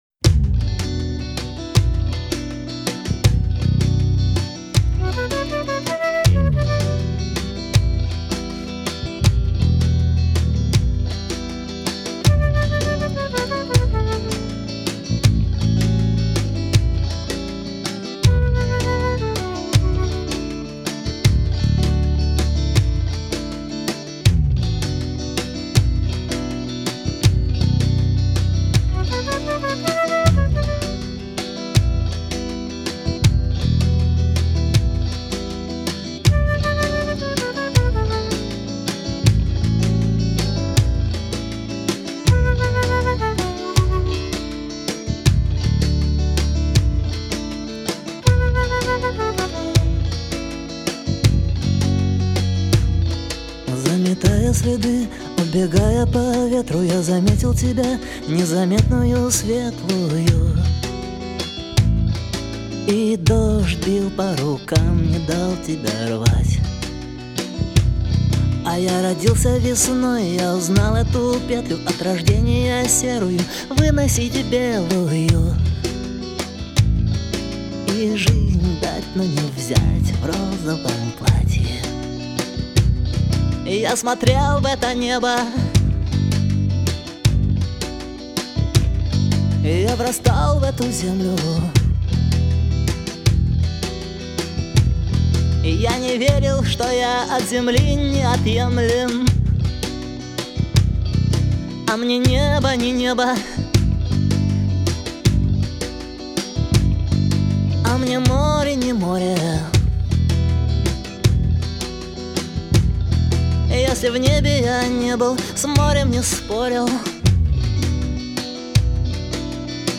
Здорово, немного музыка грустноватая, но так наверно и задумано)